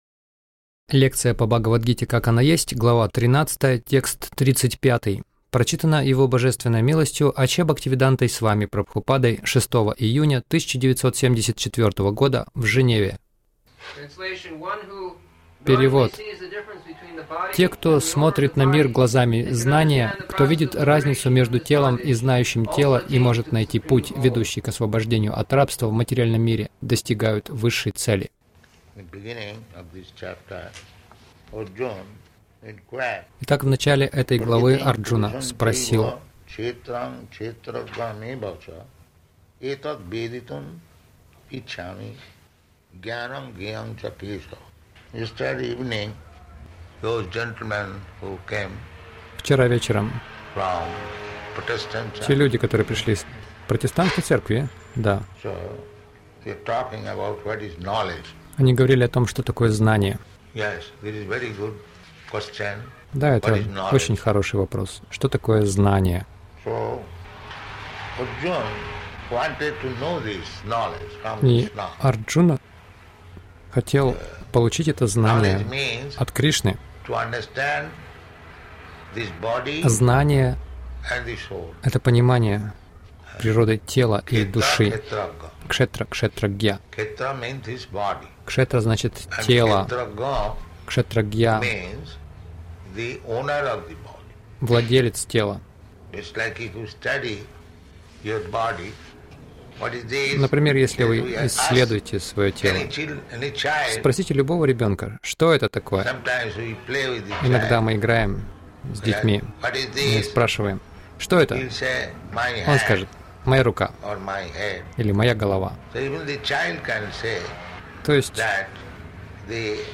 Милость Прабхупады Аудиолекции и книги 06.06.1974 Бхагавад Гита | Женева БГ 13.35 — Наше тело — поле деятельности Загрузка...